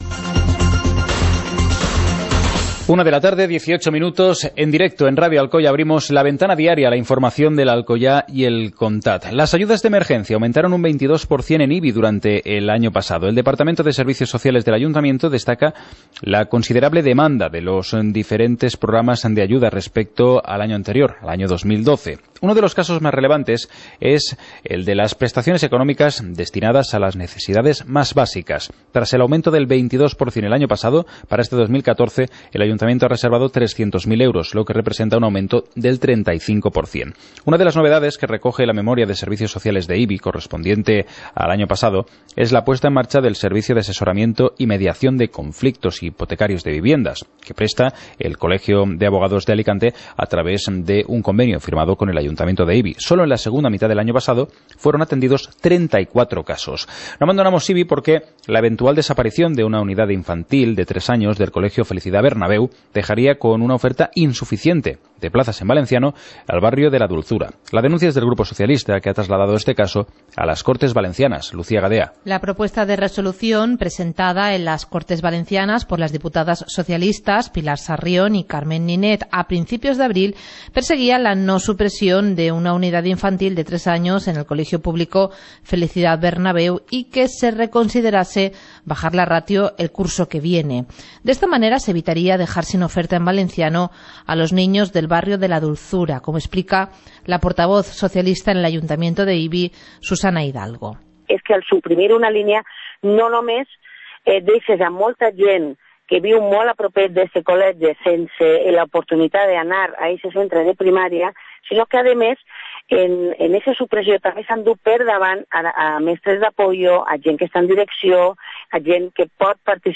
Informativo comarcal - martes, 27 de mayo de 2014